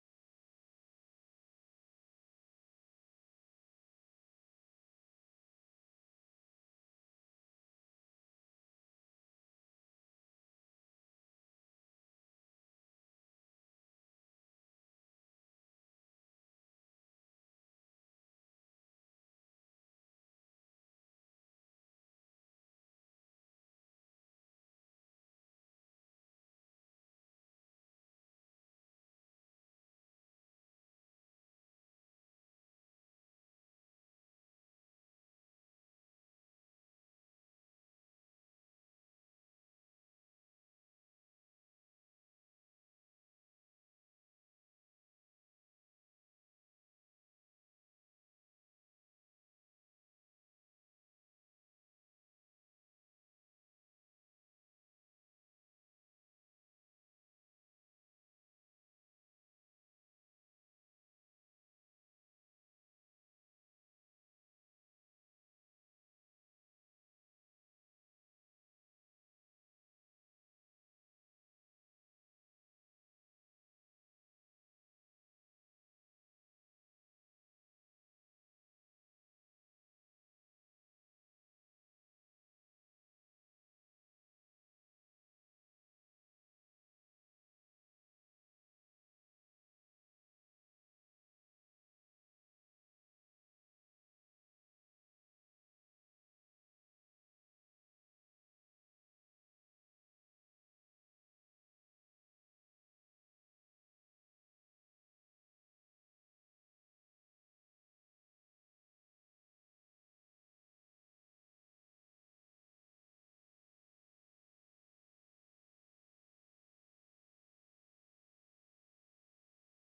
ምርጥና አዳዲስ የካንትሪ ማለትም የአሜሪካ አገር ሰብእ፣ የሮክ፣ተሶል፣የፖፕ፣የአር-ኤን-ቢ፣ብሉዝ፣የሬጌና የሂፕ-ሃፕ ዜማዎችን